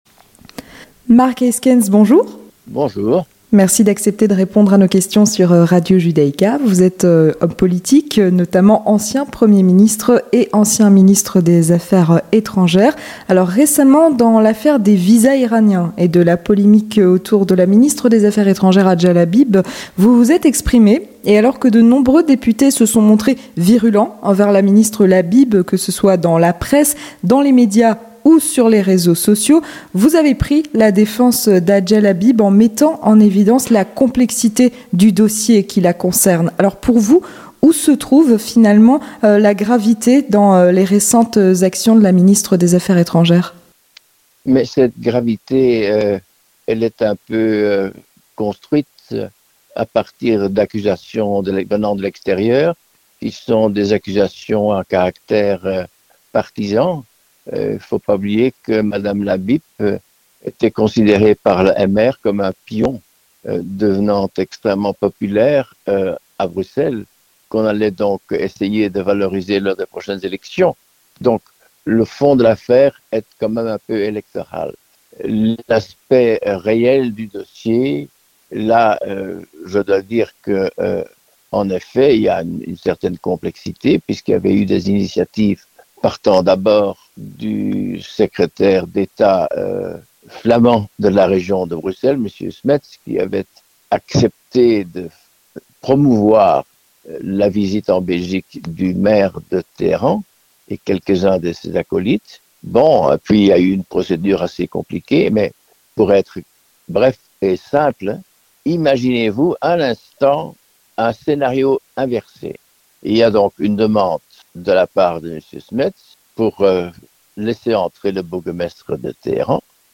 Avec Mark Eyskens, ancien premier ministre et ancien ministre des affaires étrangères